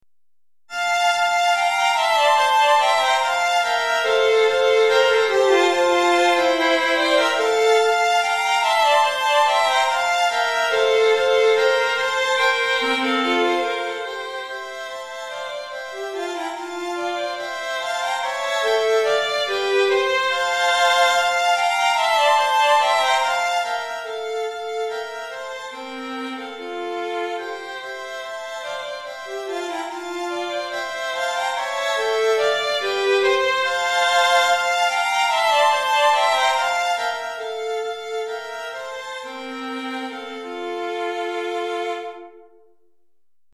3 Violons